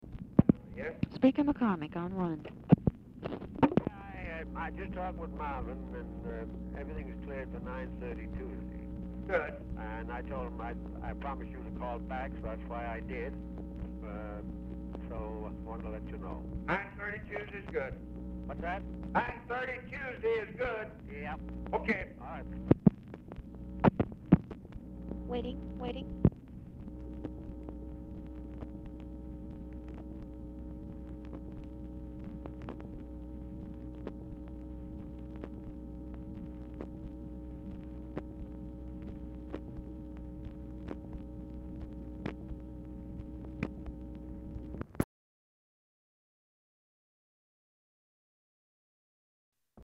Telephone conversation # 11325, sound recording, LBJ and JOHN MCCORMACK, 1/7/1967, 12:34PM | Discover LBJ
Format Dictation belt
Location Of Speaker 1 Oval Office or unknown location